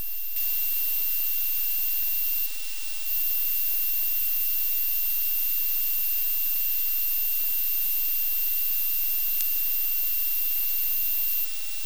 Начало » Записи » Радиоcигналы на опознание и анализ
Два сигнала на опознание